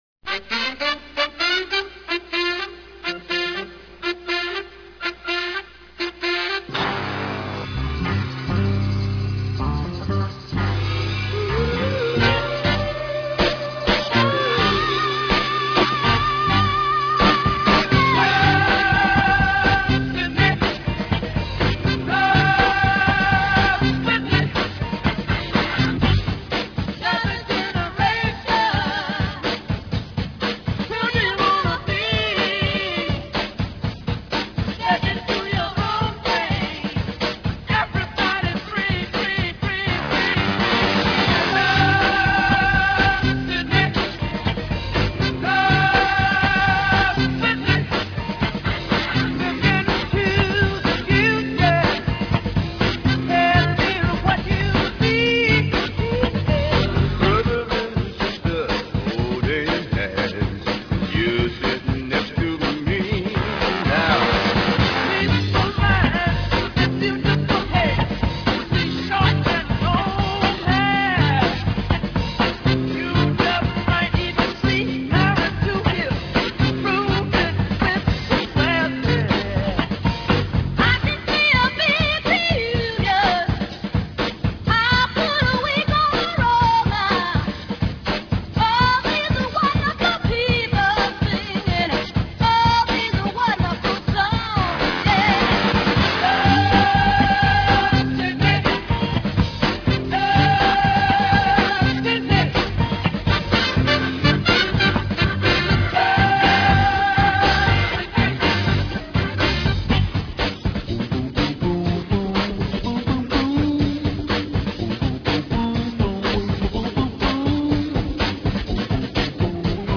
lead vocals, keyboards, everything
guitar, background vocals
lead and background vocals
drums, background vocals
saxophone, background vocals
trumpet, background vocals
bass guitar, background vocals